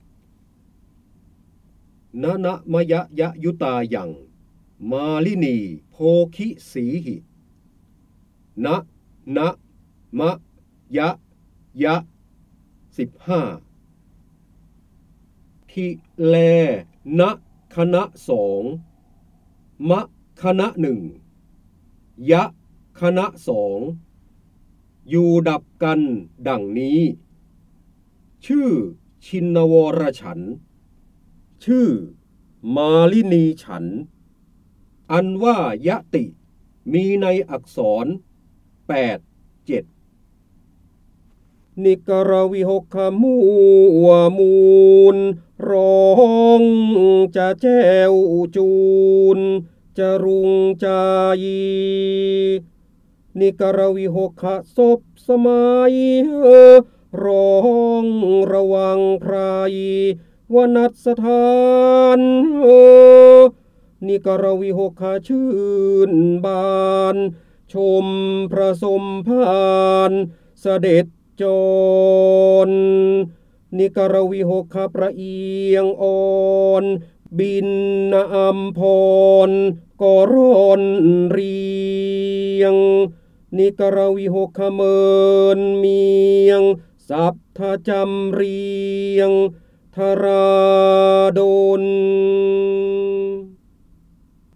เสียงบรรยายจากหนังสือ จินดามณี (พระโหราธิบดี) นนมยยยุตายํมาลินีโภคิสีหิ
คำสำคัญ : ร้อยกรอง, การอ่านออกเสียง, พระเจ้าบรมโกศ, ร้อยแก้ว, จินดามณี, พระโหราธิบดี